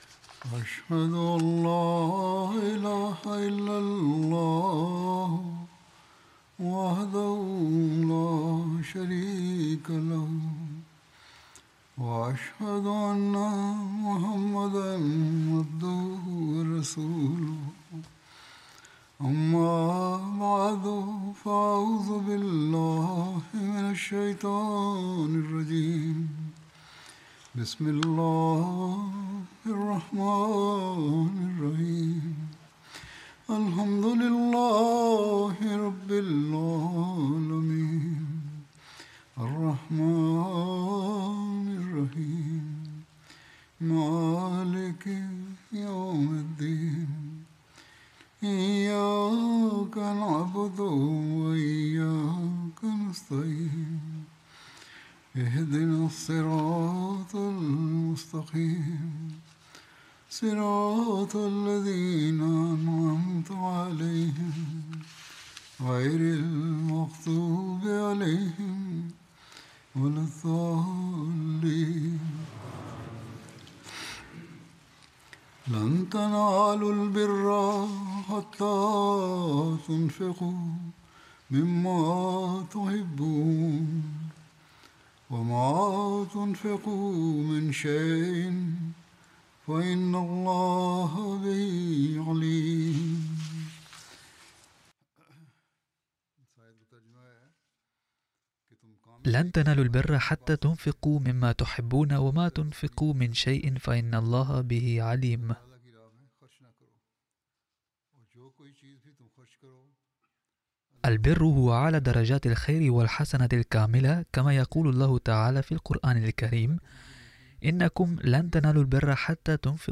Arabic Friday Sermon by Head of Ahmadiyya Muslim Community
Arabic Translation of Friday Sermon delivered by Khalifatul Masih